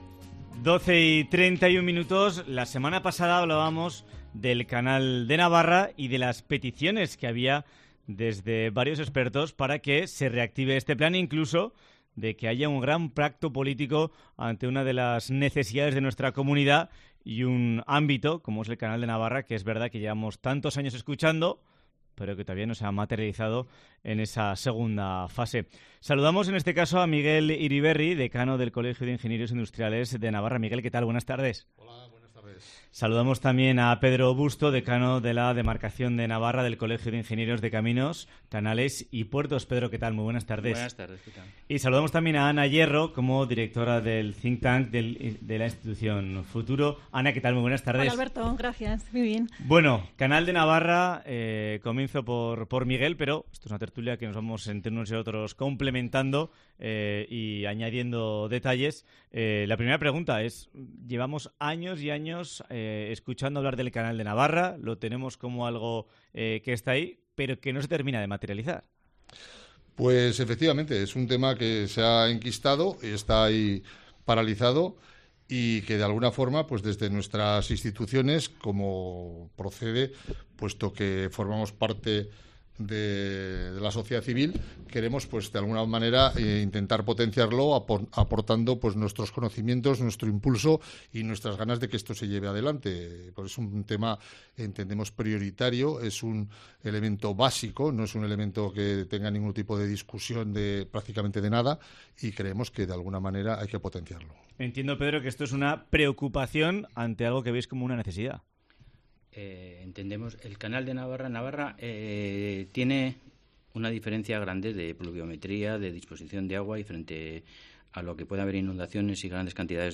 Tertulia sobre el Canal de Navarra